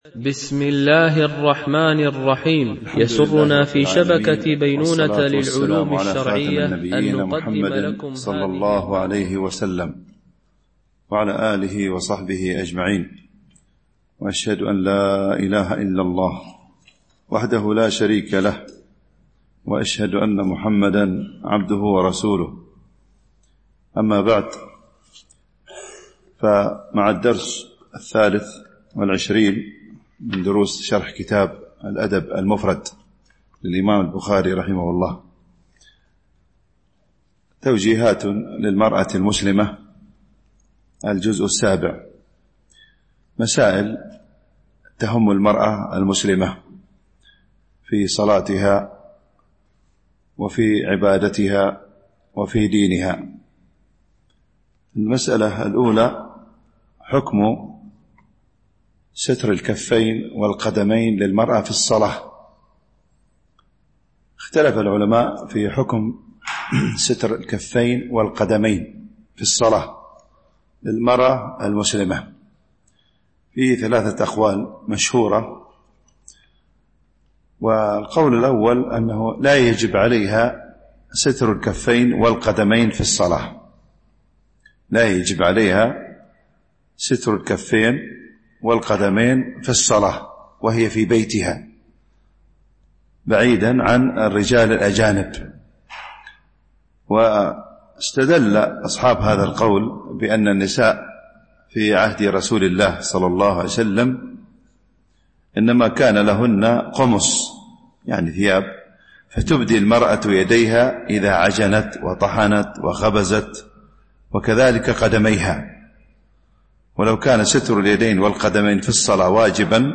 شرح الأدب المفرد للبخاري ـ الدرس 23 ( الحديث 119-121 )